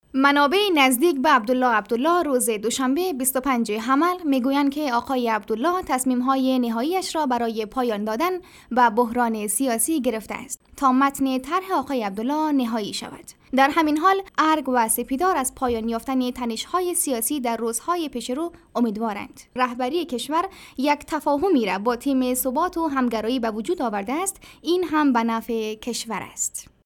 Female
Young
News